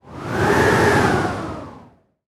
chilling-wind-noise